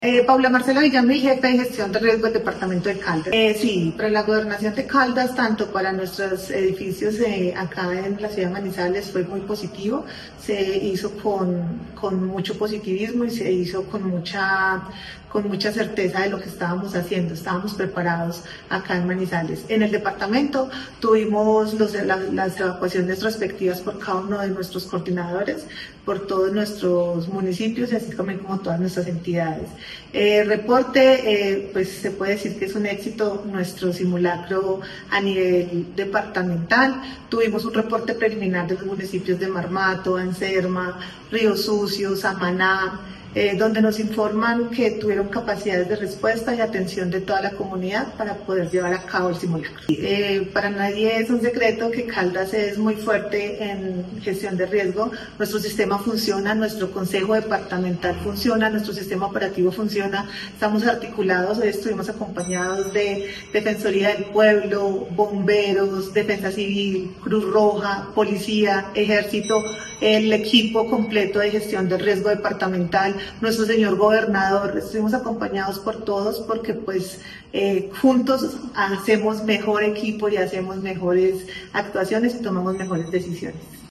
Paula Marcela Villamil Rendón, jefe de Gestión del Riesgo de Caldas.